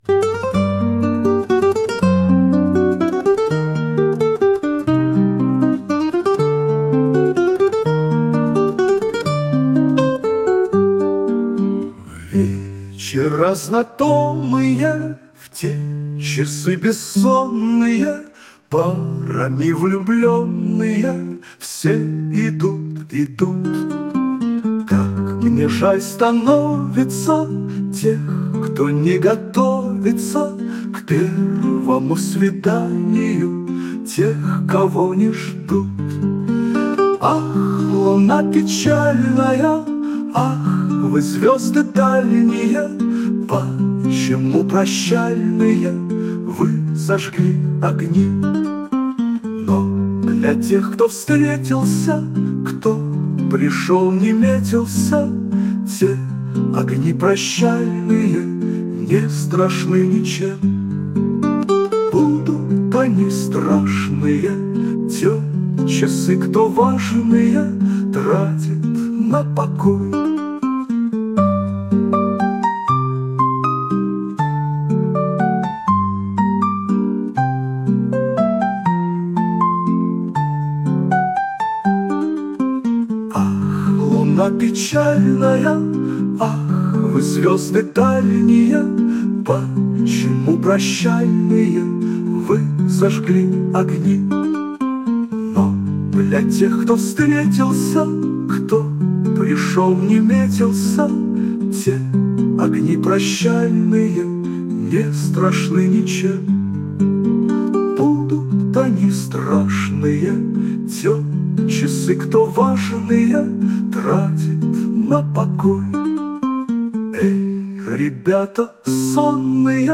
При создании песен использую ИИ.
ТИП: Пісня
СТИЛЬОВІ ЖАНРИ: Ліричний
12 гарна пісня лірична hi
Мелодичное и напевное творение! hi